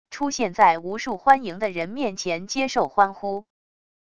出现在无数欢迎的人面前接受欢呼wav音频